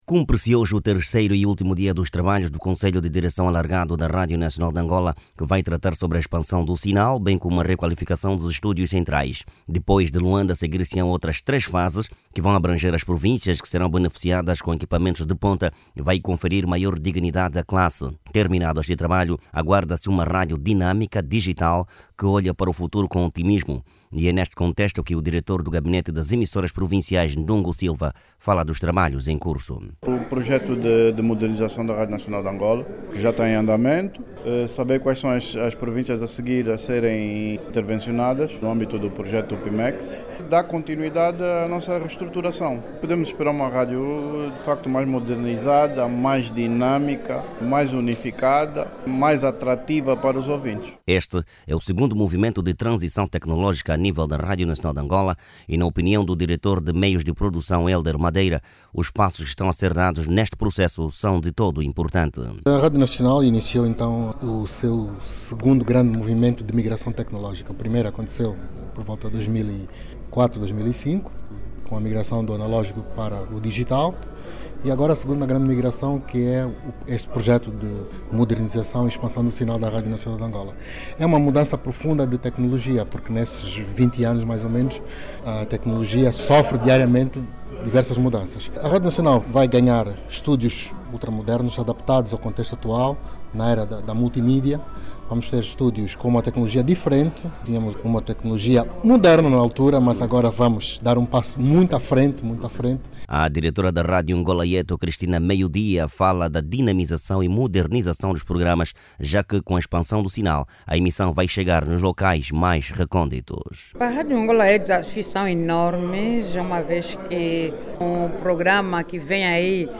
A reportagem a seguir é com o Jornalista